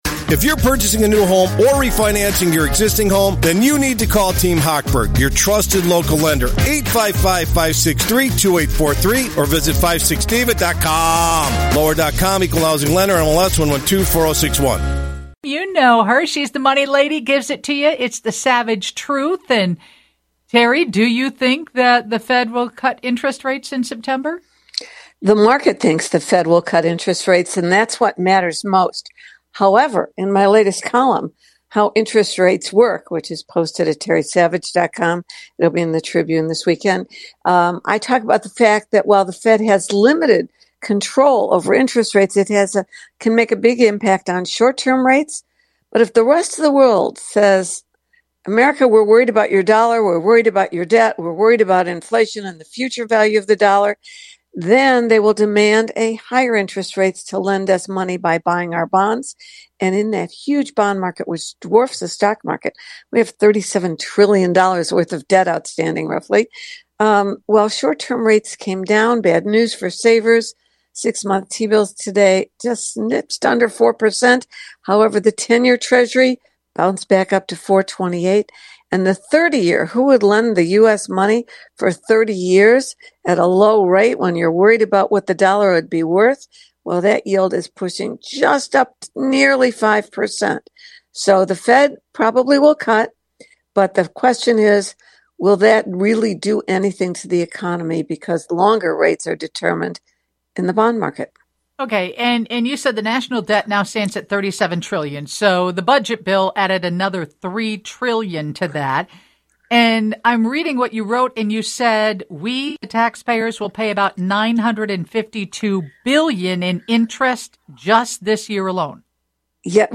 She reviews her upcoming column. And, as always, she answers questions from listeners.